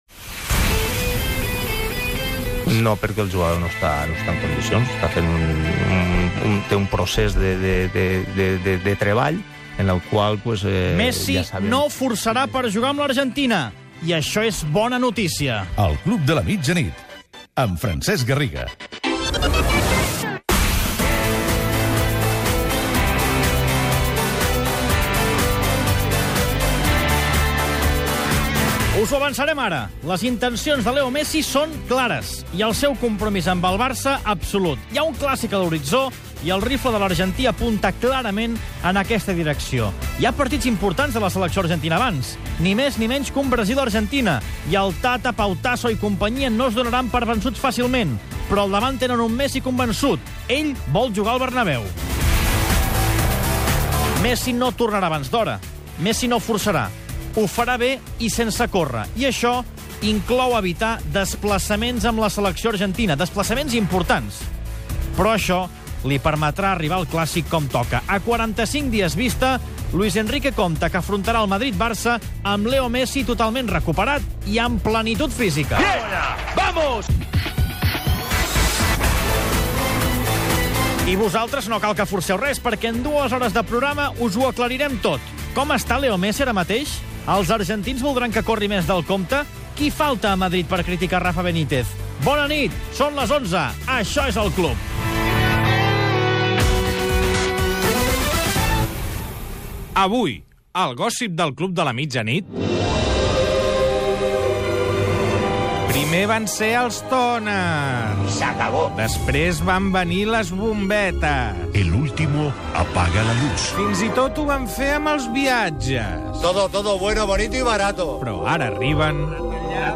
Titular, indicatiu del programa, Leo Messi prioritza el F.C:Barcelona i no forçarà per jugar amb la selecció Argentina contra el Brasil, perquè vol arribar al clàssic amb el Real Madrid en òptimes condicions.
Esportiu